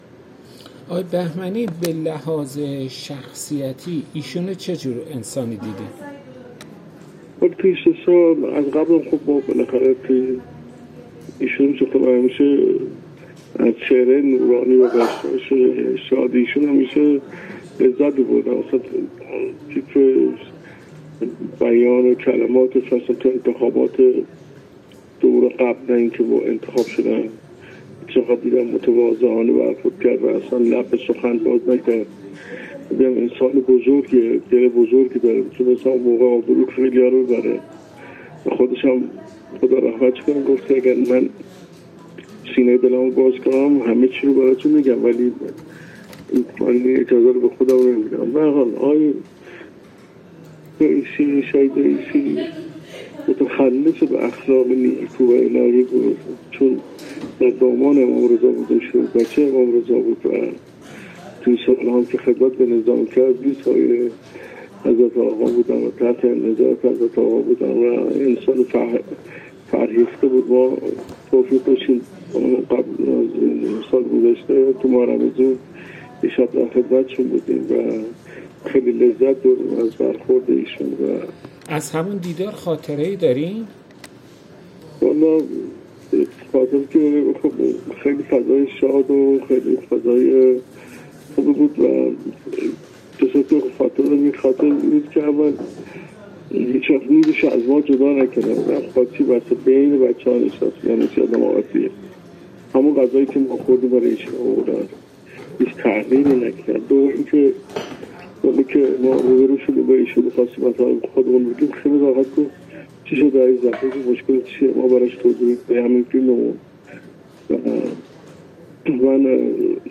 در گفت‌وگو با ایکنا: